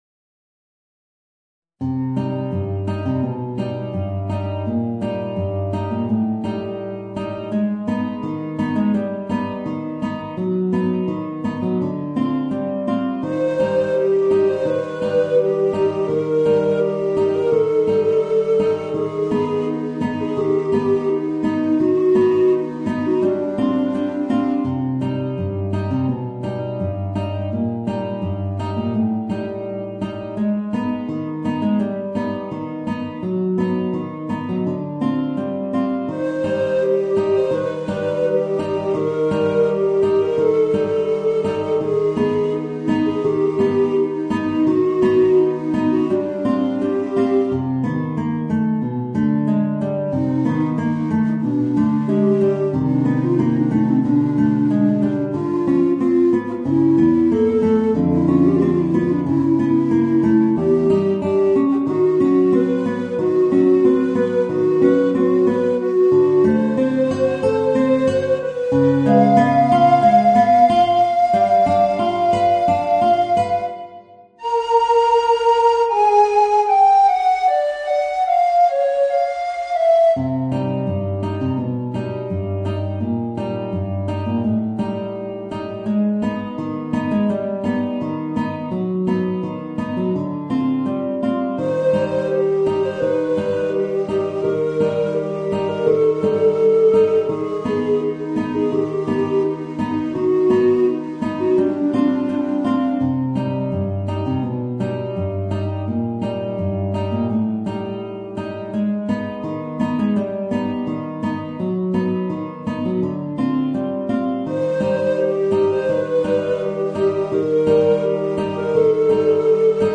Voicing: Guitar and Tenor Recorder